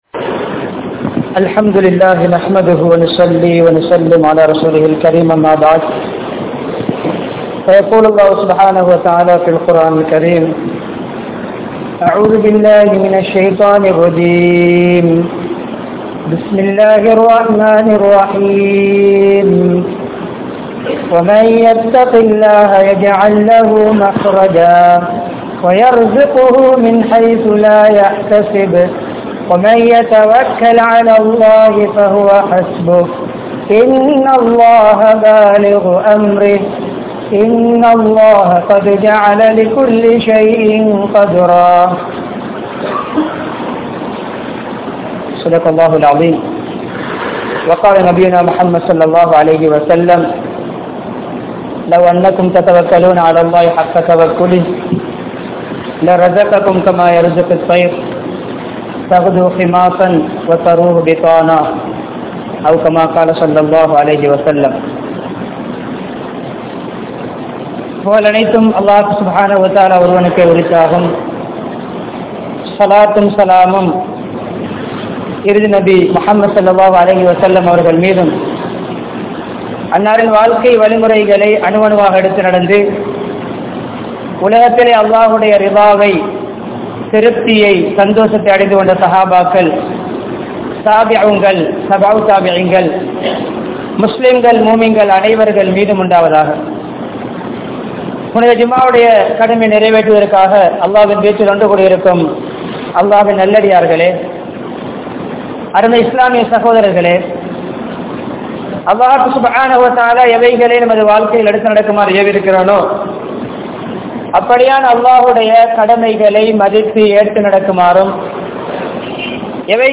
Thawakkul Entral Enna? (தவக்குல் என்றால் என்ன?) | Audio Bayans | All Ceylon Muslim Youth Community | Addalaichenai
Wellampittiya, Sedhawatte, Ar Rahmath Jumua Masjidh